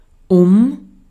Ääntäminen
Synonyymit handeln für Ääntäminen : IPA: /ʊm/ Haettu sana löytyi näillä lähdekielillä: saksa Käännös Prepositiot 1. ympäri 2. ympärille 3. ympärillä 4. reunalle 5. reunalla Muut/tuntemattomat 6. noin 7.